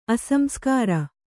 ♪ asamskāra